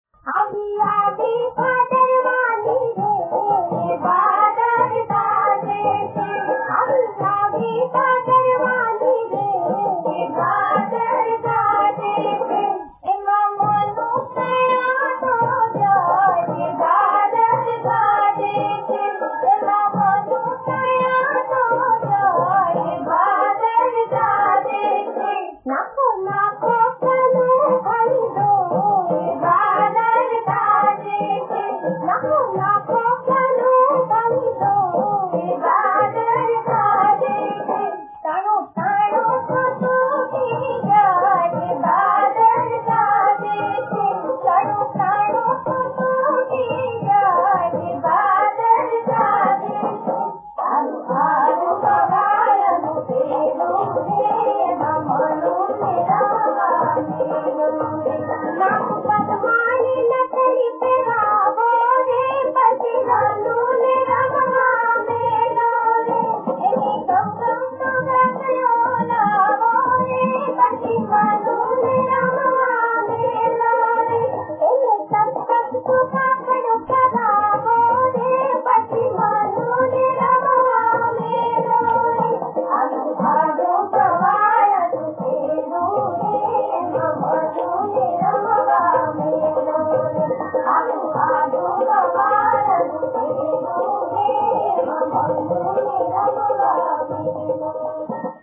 સાંજીમાં ગવાતું ફટાણું